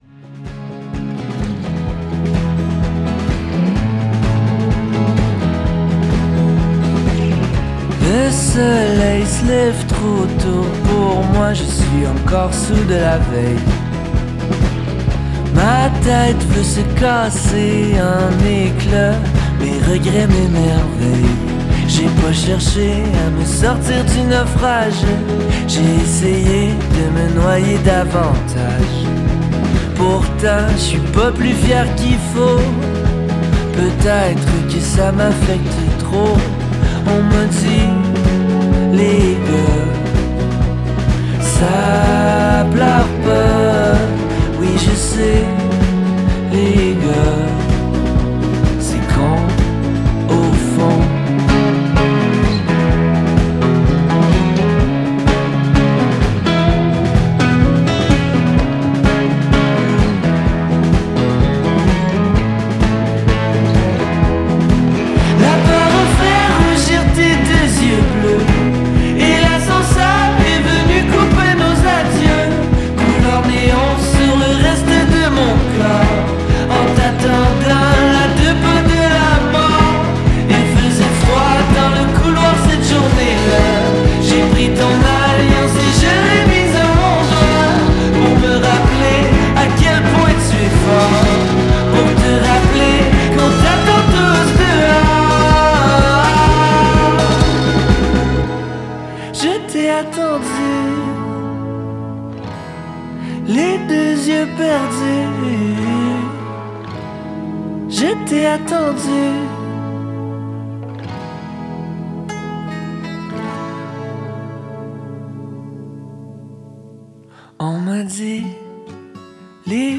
Prenant d’assaut la scène indie rock
son deuxième album aux musicalités plus rock et assumée